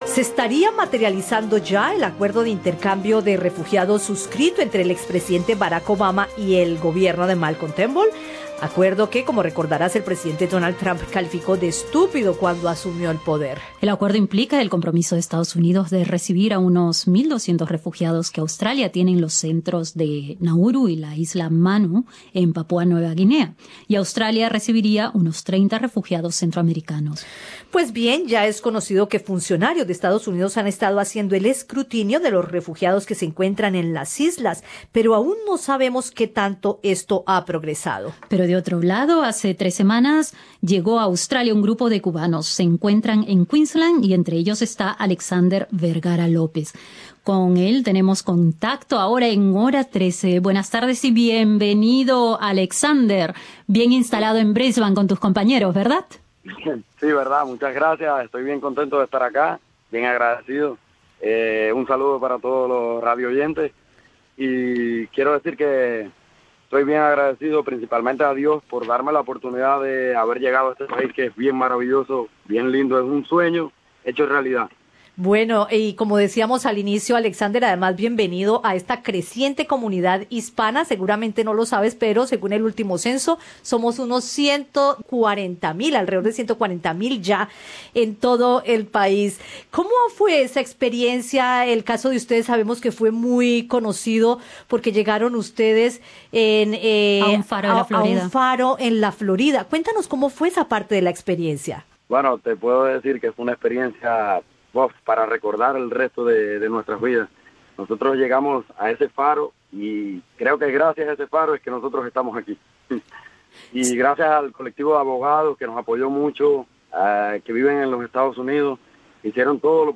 Escucha la entrevista en el podcast en la parte superior de la página.